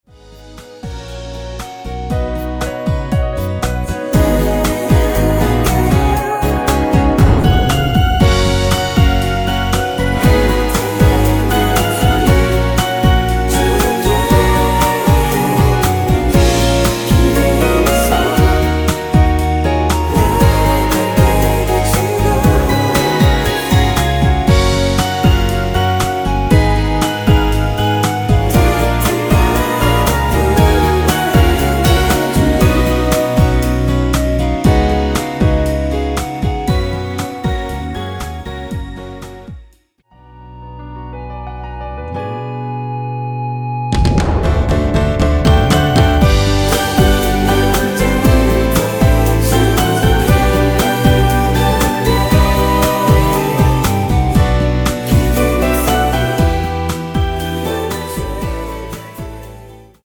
원키에서(+3)내린 멜로디와 코러스 포함된 MR 입니다.(미리듣기 참조)
F#
노래방에서 노래를 부르실때 노래 부분에 가이드 멜로디가 따라 나와서
앞부분30초, 뒷부분30초씩 편집해서 올려 드리고 있습니다.
중간에 음이 끈어지고 다시 나오는 이유는